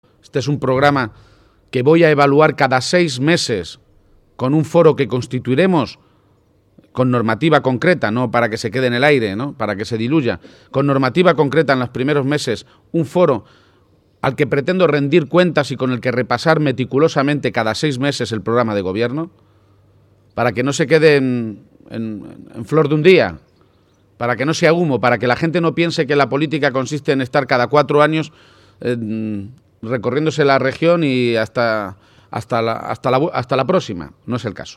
Audio Page-presentación programa electoral 4